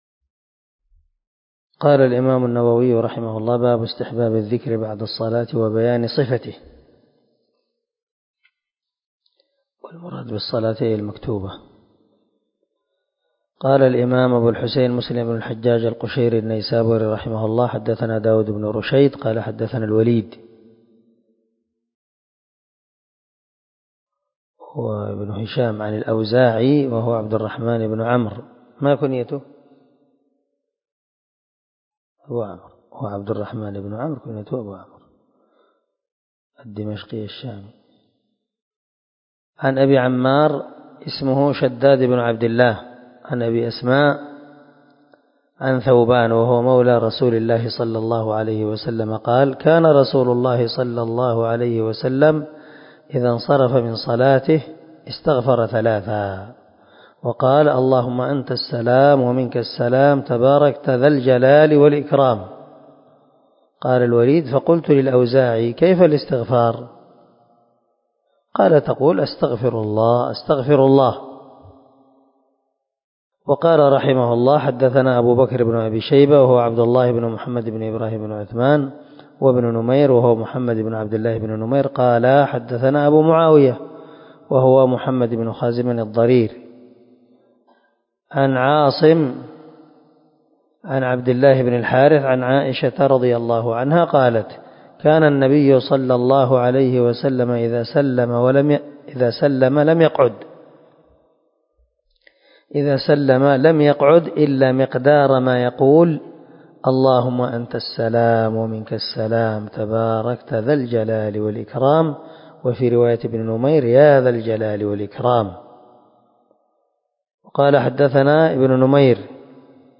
374الدرس 46 من شرح كتاب المساجد ومواضع الصلاة حديث رقم ( 591 - 592 )من صحيح مسلم